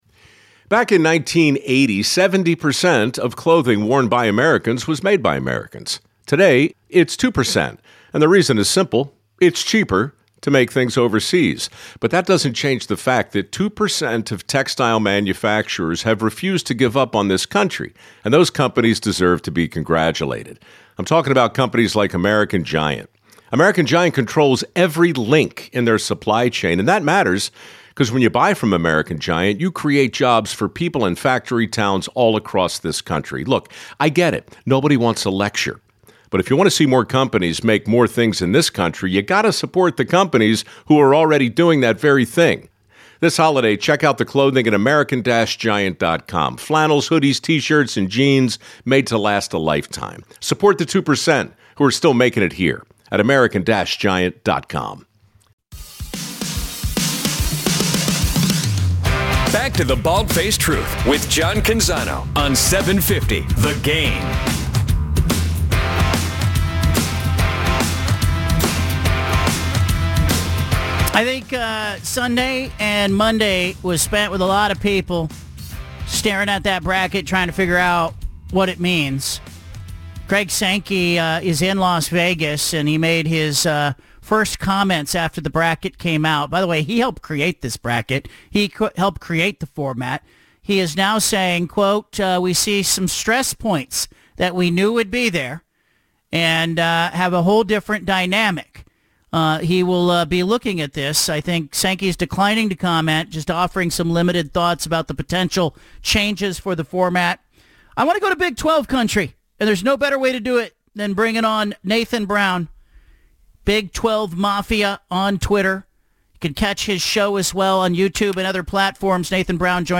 BFT Interview